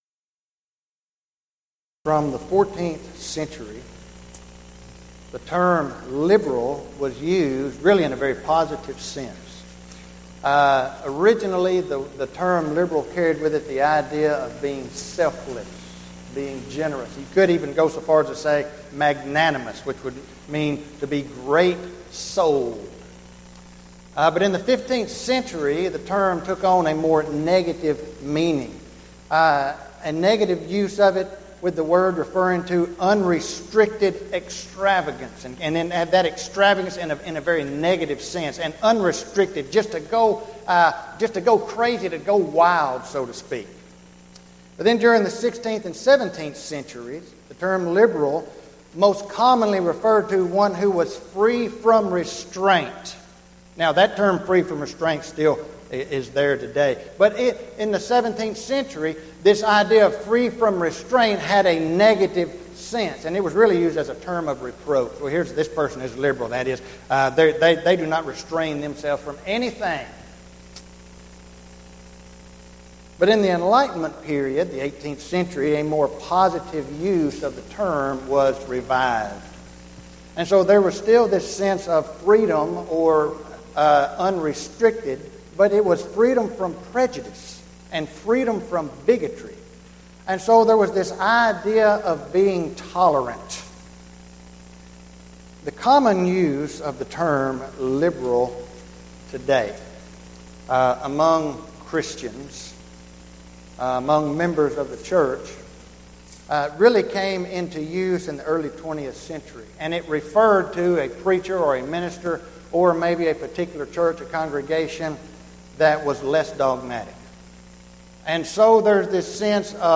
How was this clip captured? Event: 4th Annual Arise Workshop Theme/Title: Biblical Principles for Congregational Growth